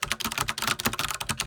sfx_keyboard.ogg